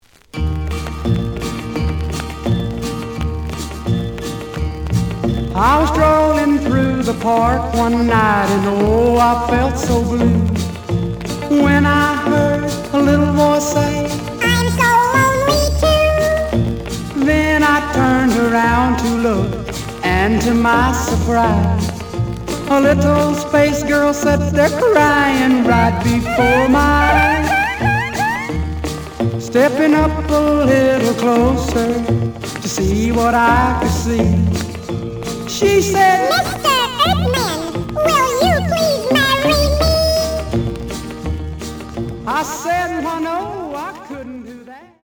試聴は実際のレコードから録音しています。
●Genre: Rhythm And Blues / Rock 'n' Roll
盤に若干の歪み。